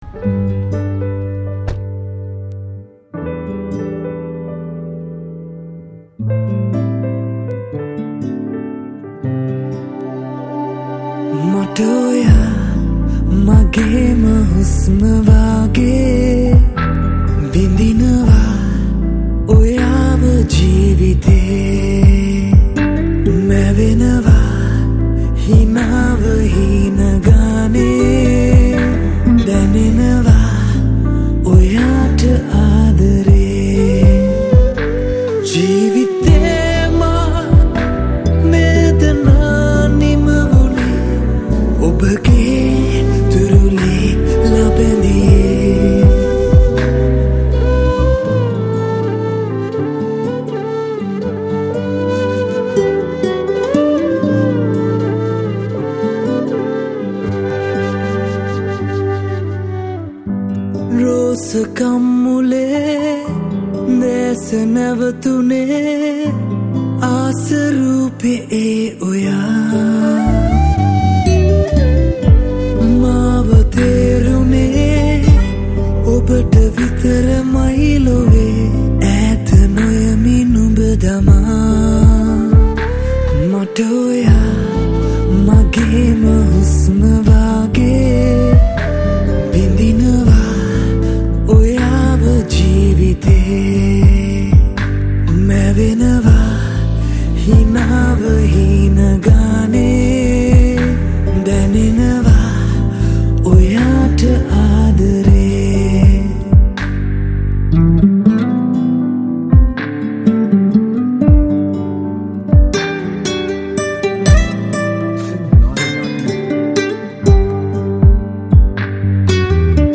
Guitars